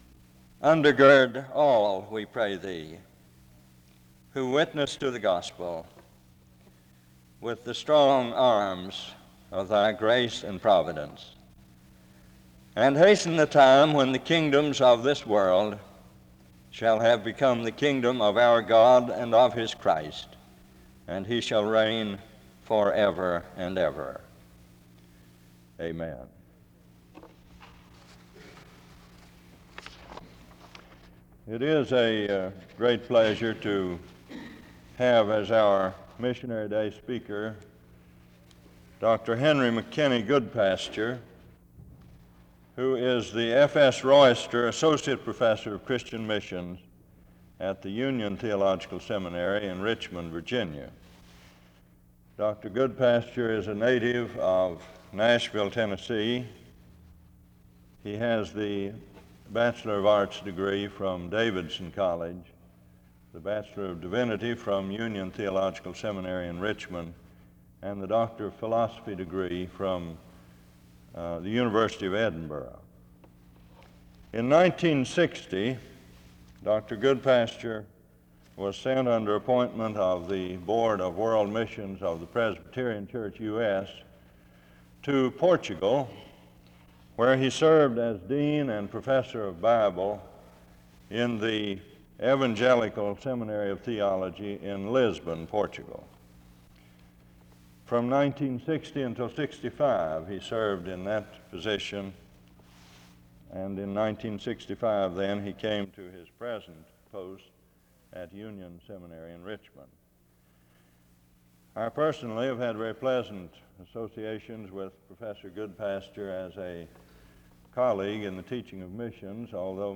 SEBTS Missionary Day Address